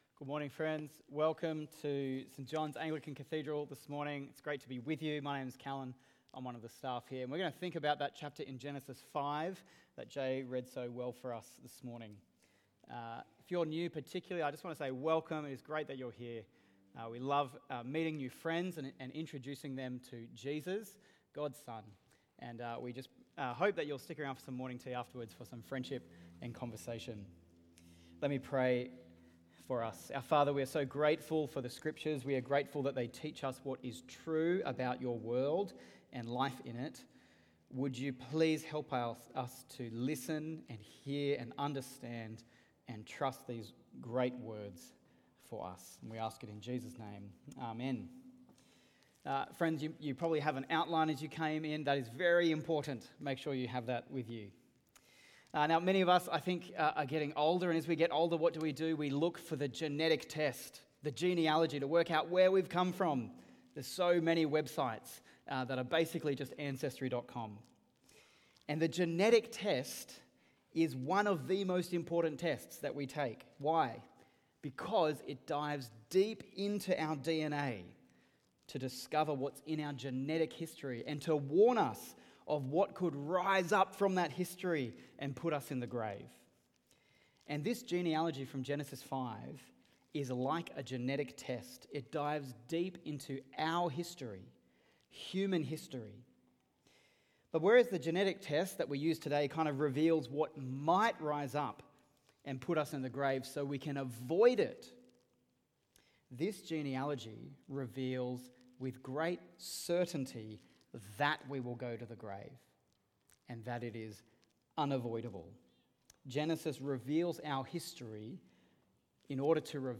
Watch the full service on YouTube or listen to the sermon audio only.